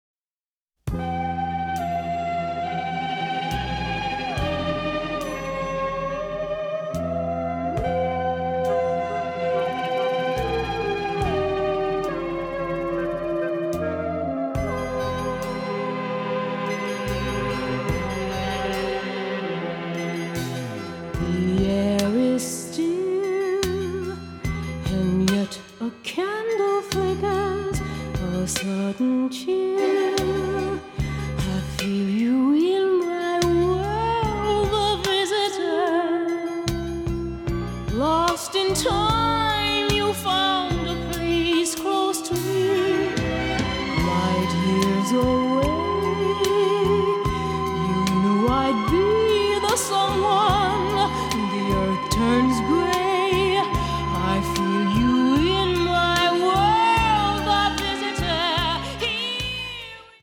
performed by a studio singer in New York